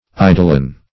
Search Result for " idolon" : The Collaborative International Dictionary of English v.0.48: Idolum \I*do"lum\, Idolon \I*do"lon\, n.; pl.
idolon.mp3